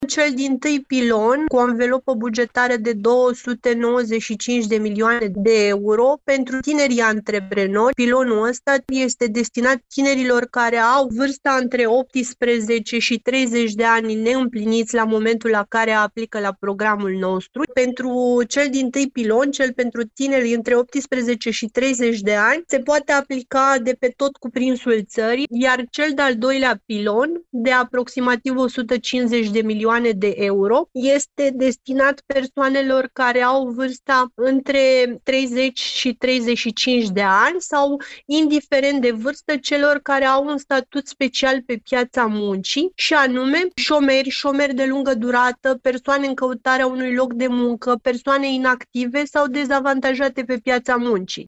i-a declarat într-un interviu colegului nostru